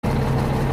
There are some sound files which cause a "beep" before being started from a script.
Here is an example that causes a beep: